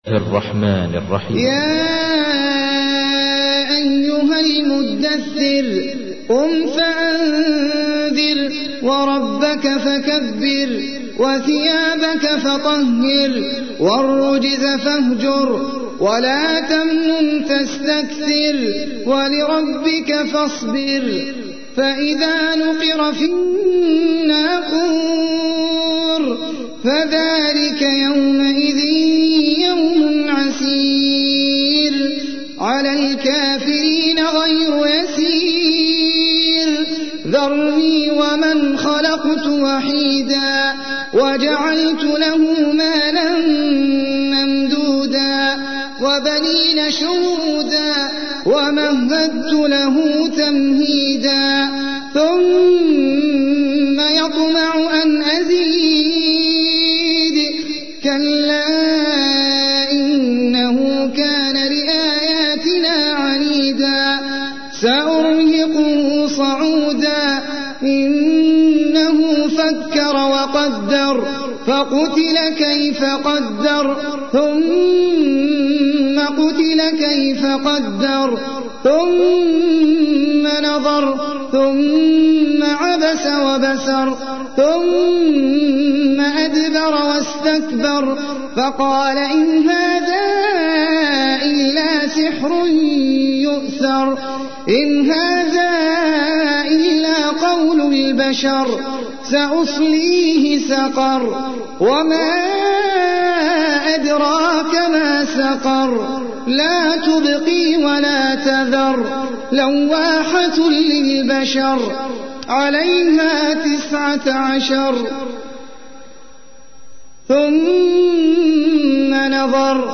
تحميل : 74. سورة المدثر / القارئ احمد العجمي / القرآن الكريم / موقع يا حسين